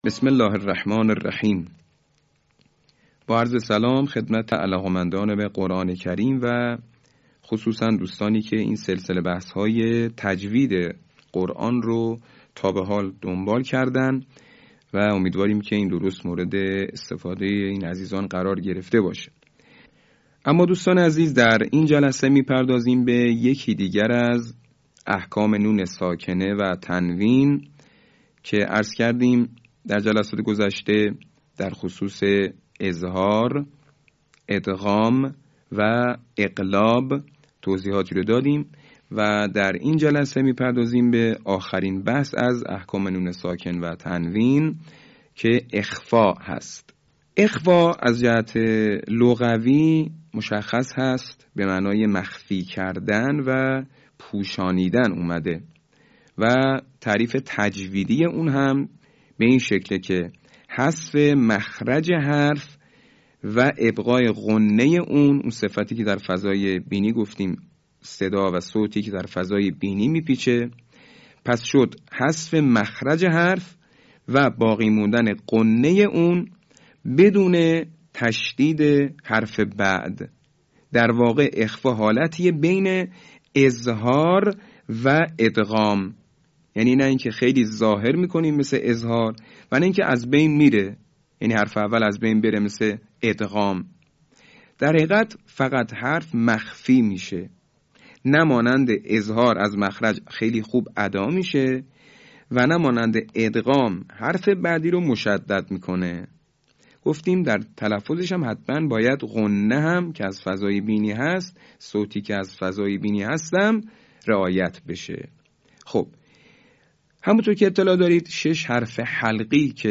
صوت | آموزش تجویدی احکام اخفاء